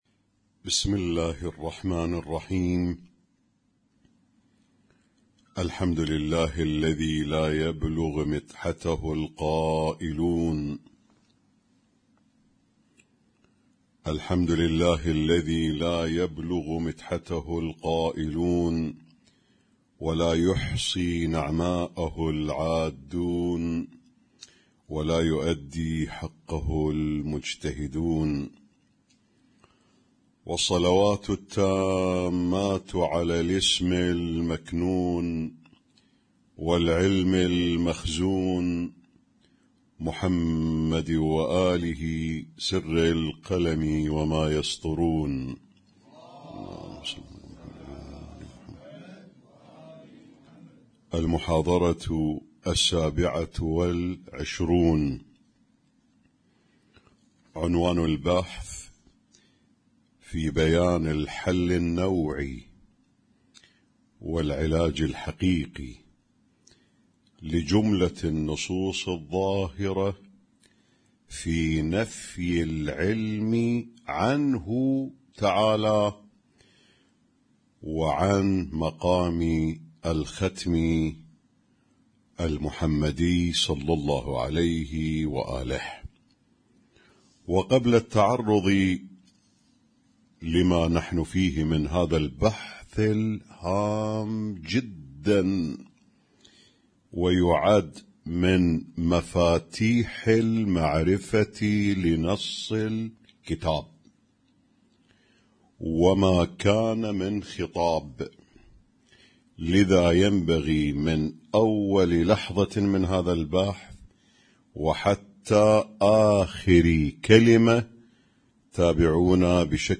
Husainyt Alnoor Rumaithiya Kuwait
اسم التصنيف: المـكتبة الصــوتيه >> الدروس الصوتية >> الرؤية المعرفية الهادفة